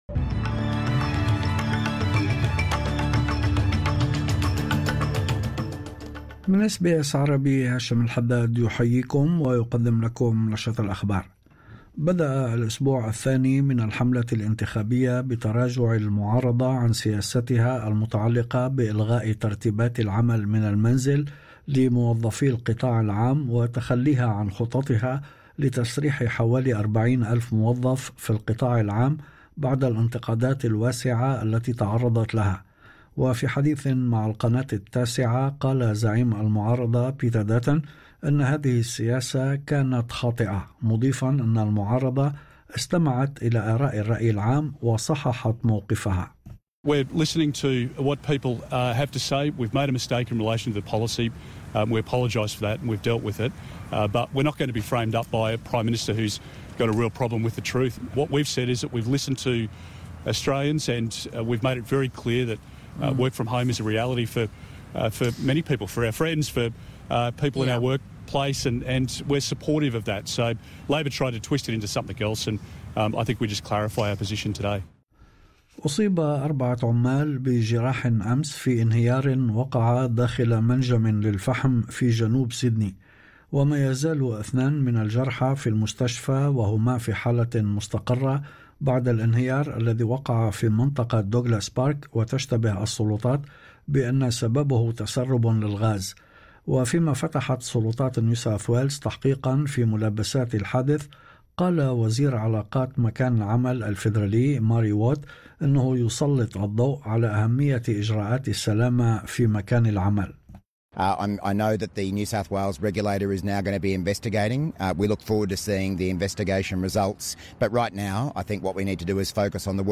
نشرة أخبار الظهيرة 07/04/2025